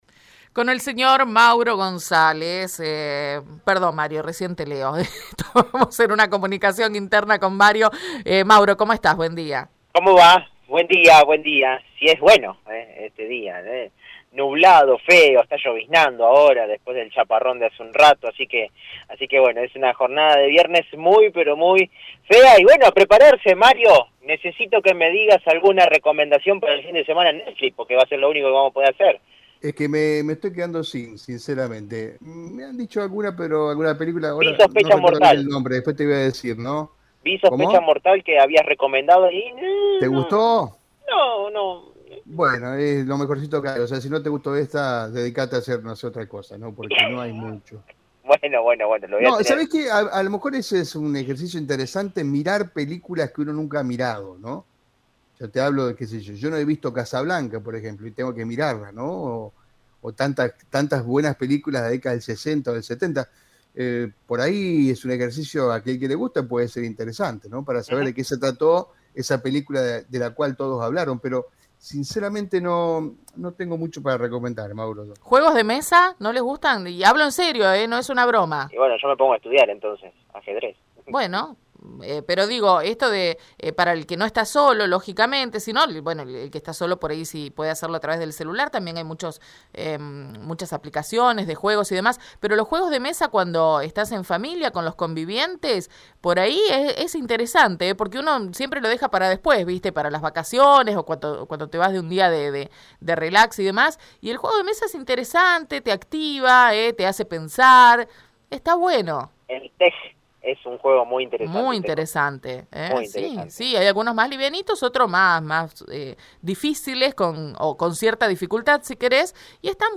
En diálogo con el móvil de Radio EME